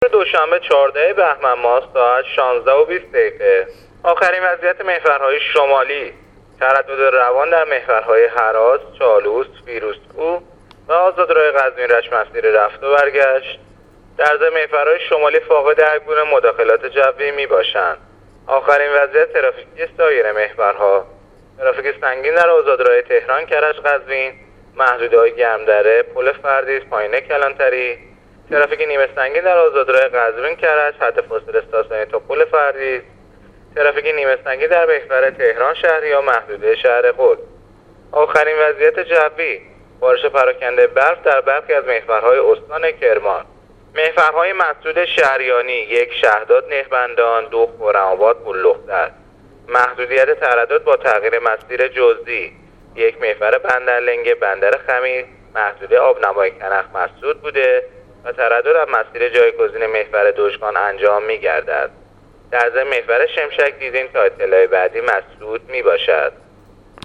گزارش رادیو اینترنتی از آخرین وضعیت ترافیکی جاده‌ها تا ساعت ۱۶:۲۰ دوشنبه ۱۴ بهمن‌ماه۱۳۹۸